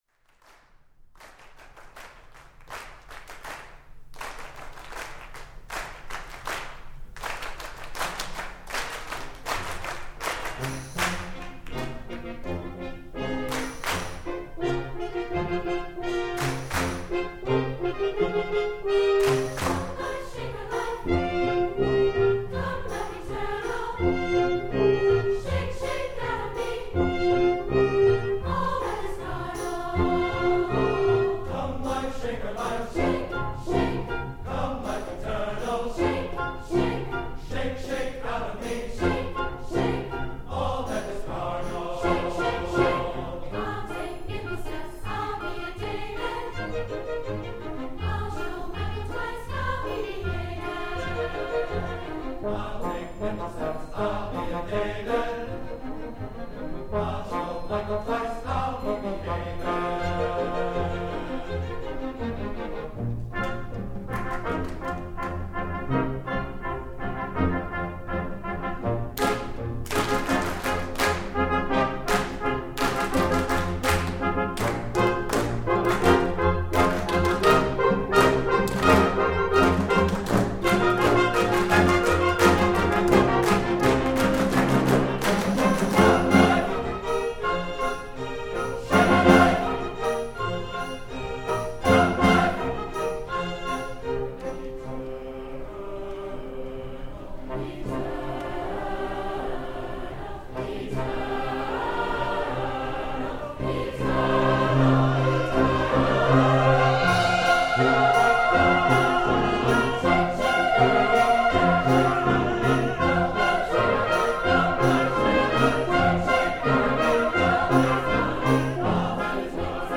for SATB Chorus, Children's Chorus, and Orchestra (2002)